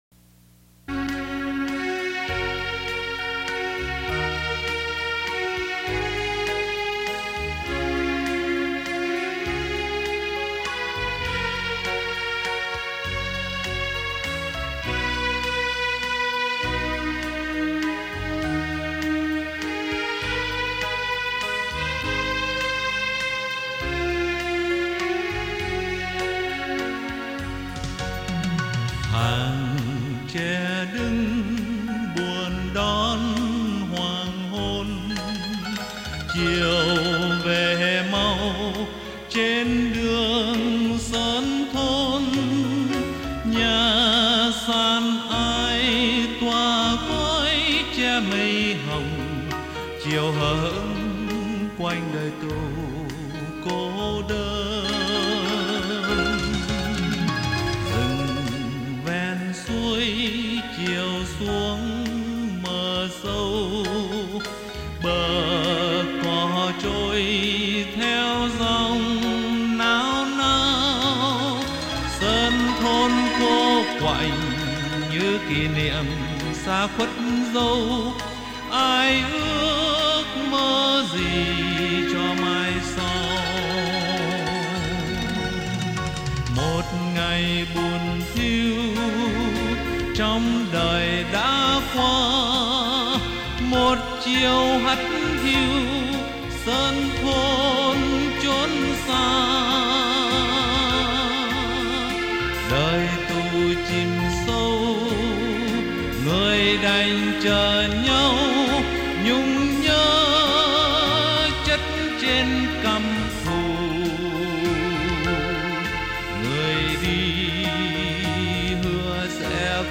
-một bản viết với cung F# trưởng với 6 dấu thăng, đúng với cao độ bài nhạc trong video clip.[**]
Tù Khúc